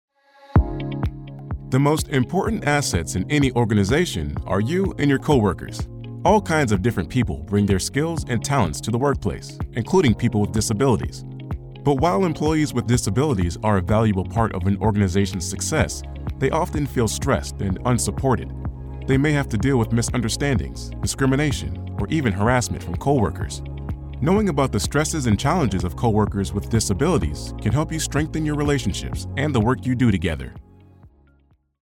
Profundo, Urbana, Amable
E-learning